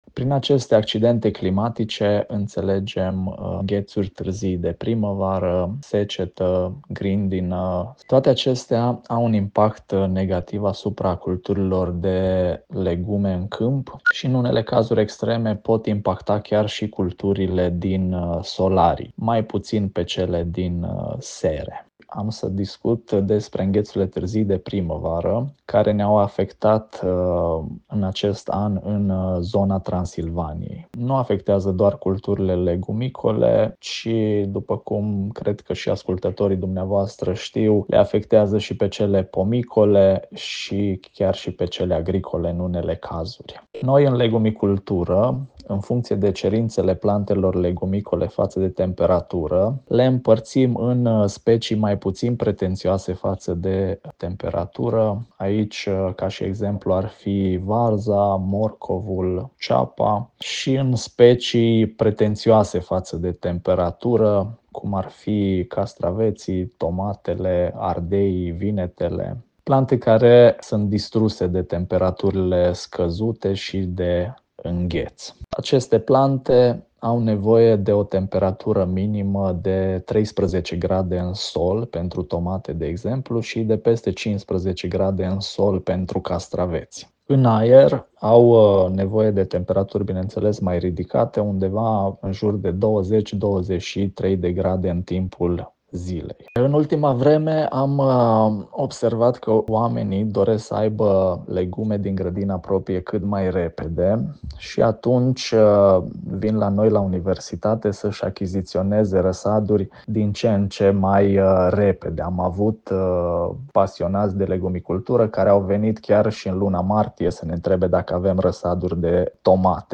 interviu-legume-afectate.mp3